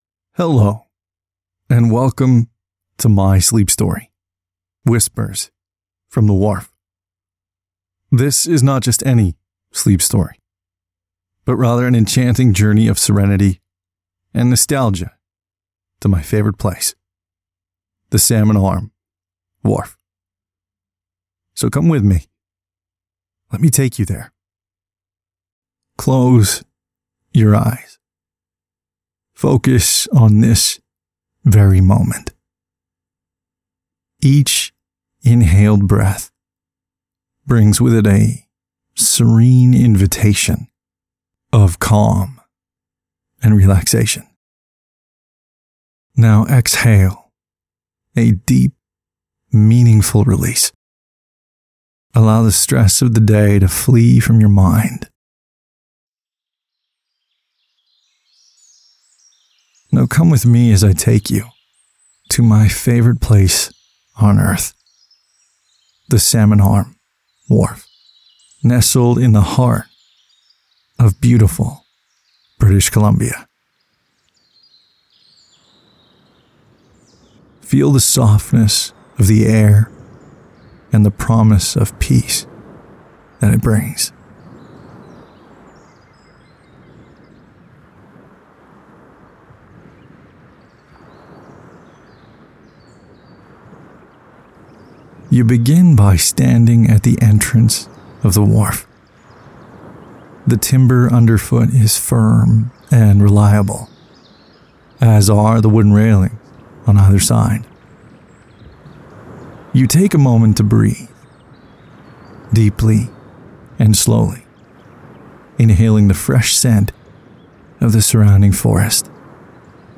Download my Sleep Story
calm-sleep-story-whispers-from-the-wharf-1.mp3